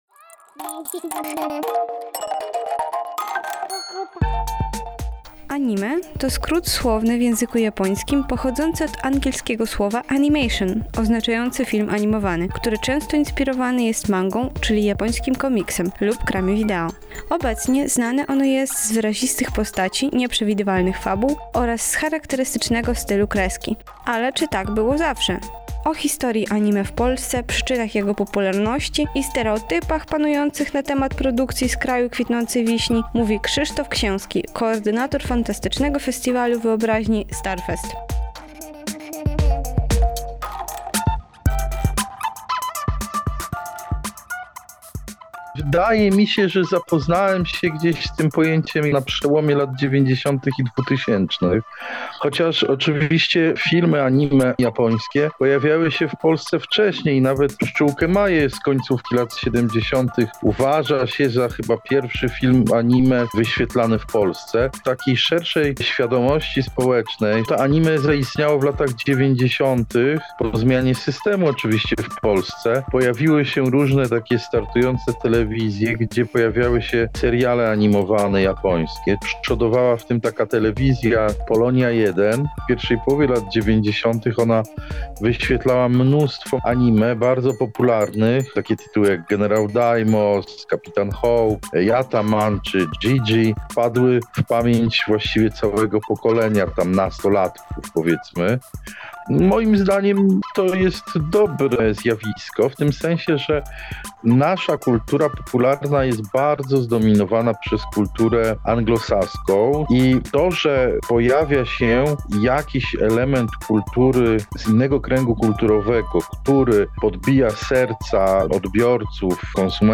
Danie Główne: Anime w popkulturze - Radio Centrum